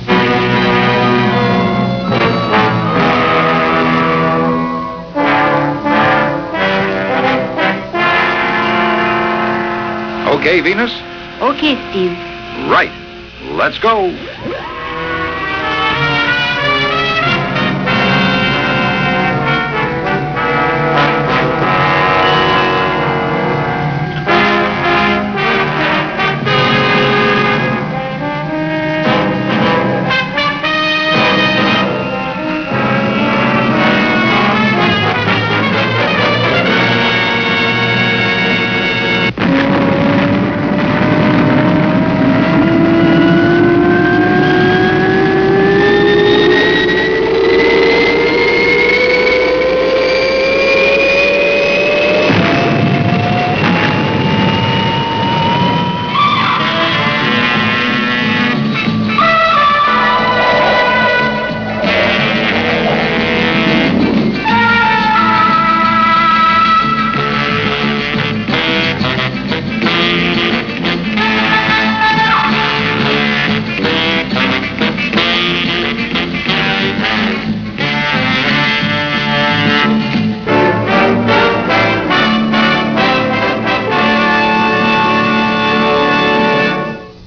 Opening Theme